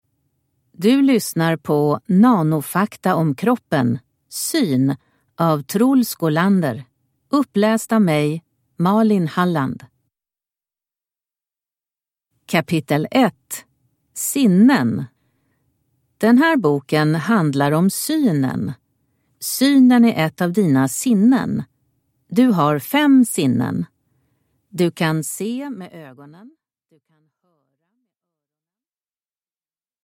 Nanofakta om kroppen. Syn – Ljudbok